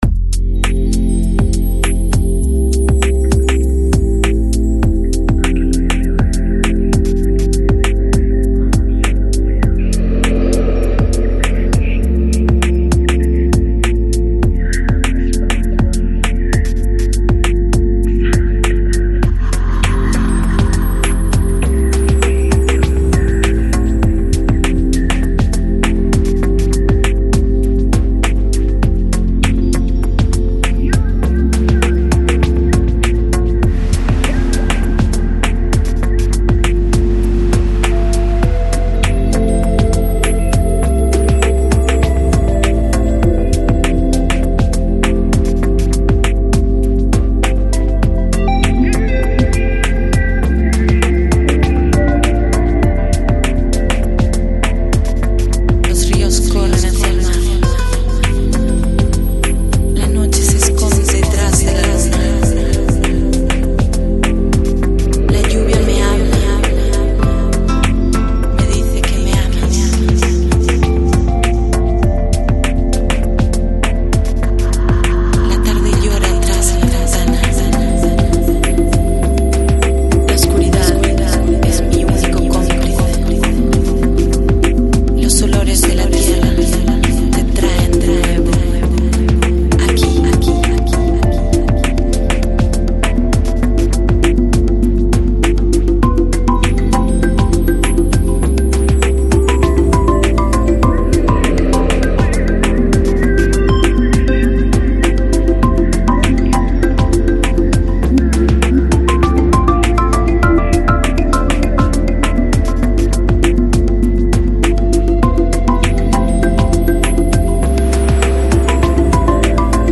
Ambient | Chillout | Downtempo